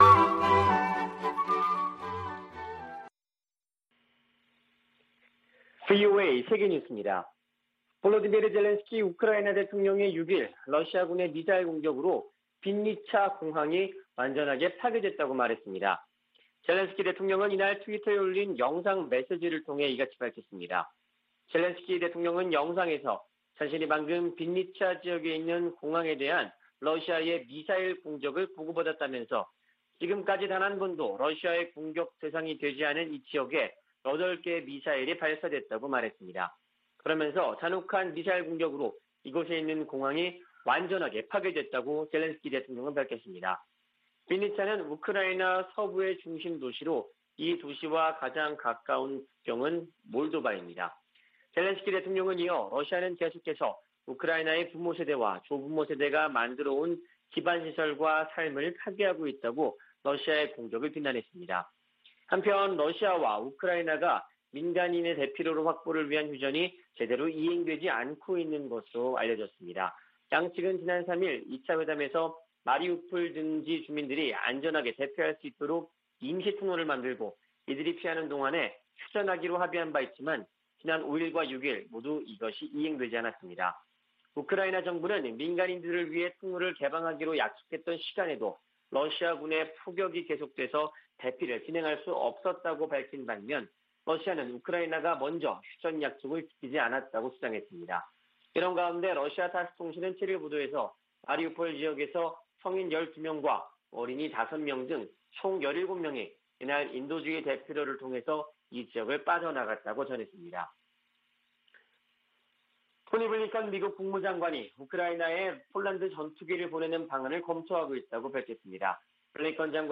VOA 한국어 아침 뉴스 프로그램 '워싱턴 뉴스 광장' 2022년 3월 8일 방송입니다. 북한은 지난 5일 또 한차례 정찰위성 개발을 위한 시험을 했다고 발표했습니다. 미 국무부는 북한 탄도미사일 시험 발사 재개를 규탄했습니다.